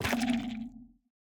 Minecraft Version Minecraft Version snapshot Latest Release | Latest Snapshot snapshot / assets / minecraft / sounds / block / sculk / place1.ogg Compare With Compare With Latest Release | Latest Snapshot